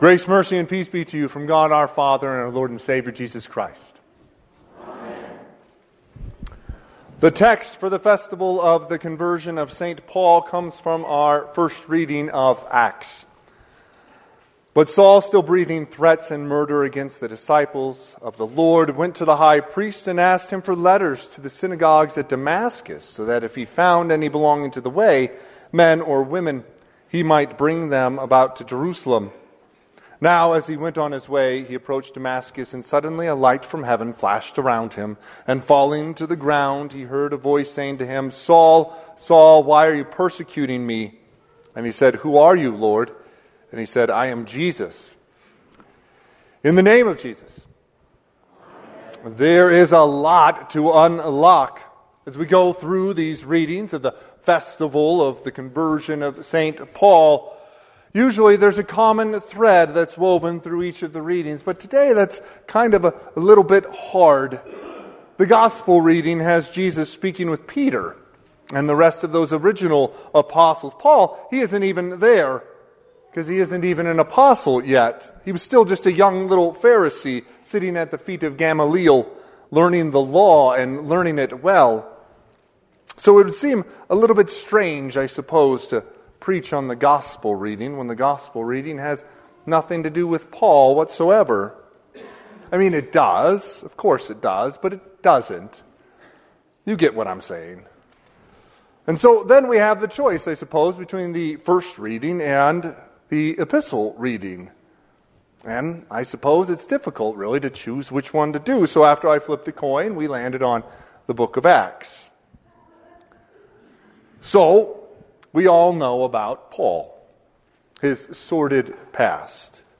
Sermon - 1/25/2026 - Wheat Ridge Evangelical Lutheran Church, Wheat Ridge, Colorado